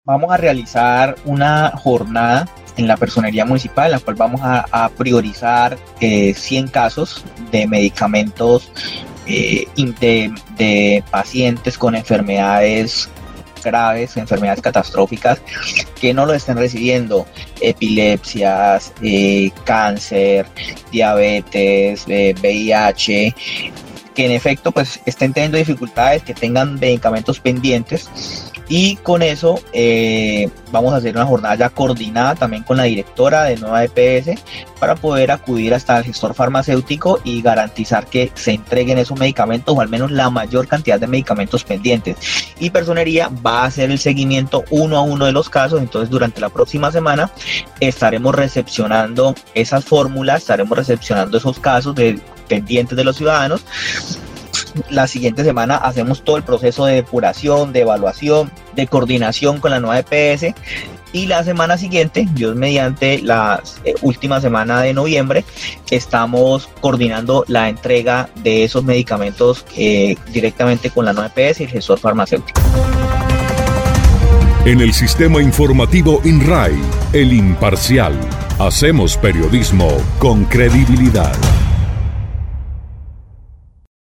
Así lo dio a conocer Jorge Luis Lara Andrade, personero del municipio de Florencia, quien dijo que, a partir de mañana martes 11 de noviembre en la sede de la entidad se recepcionaran los casos de posibles beneficiarios quienes solo tienen que presentar su documento de identidad y la o las fórmulas médicas.